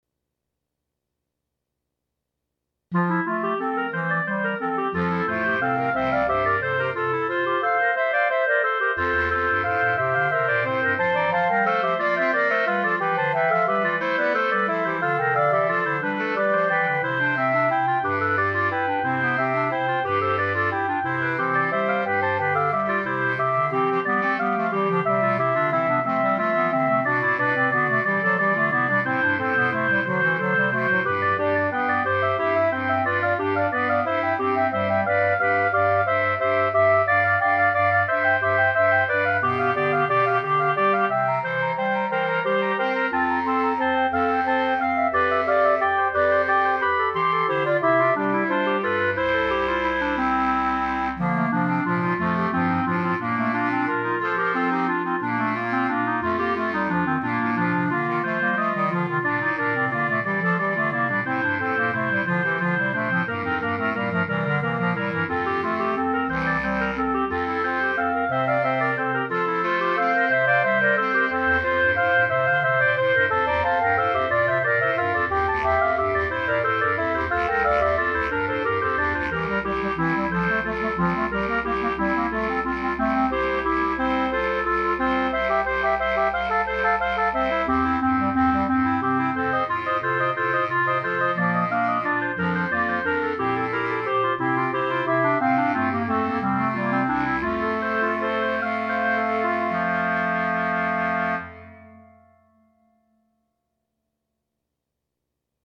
Instrumentation:3 Clarinet, Bass Cl.
A shortened version for Clarinet
Quartet of this well known
Parts for 3 Bb Clarinets plus Bass Clarinet